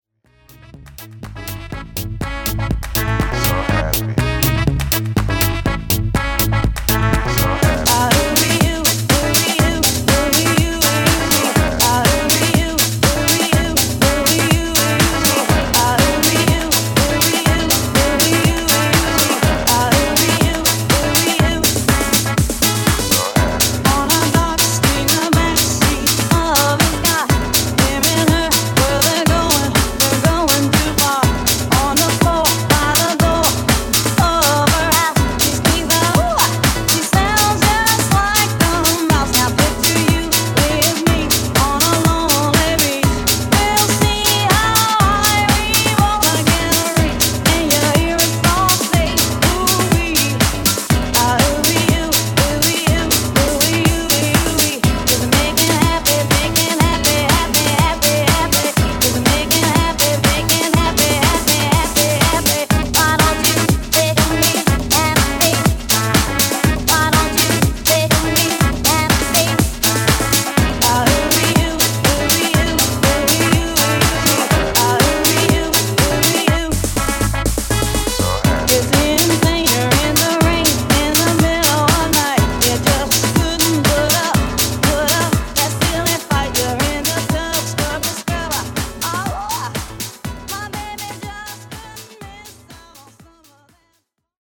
Classic House)Date Added